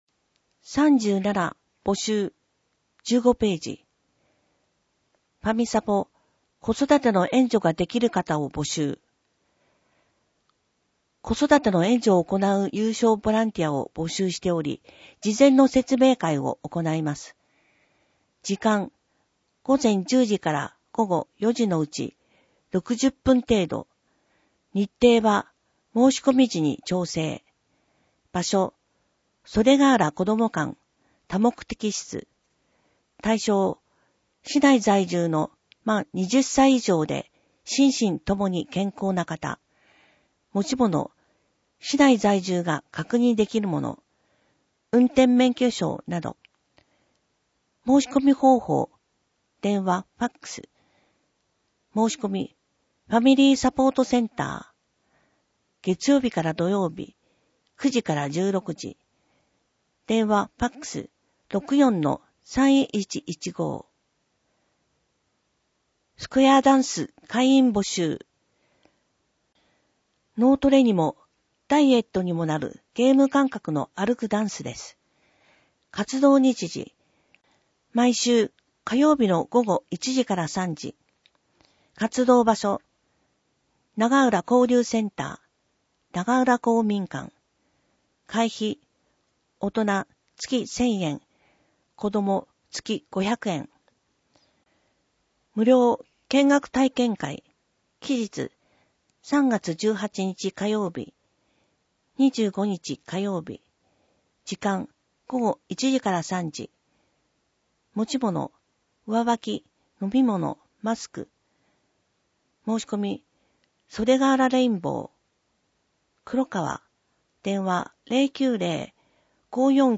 目の不自由な人などのために録音されたデイジー図書を掲載しています。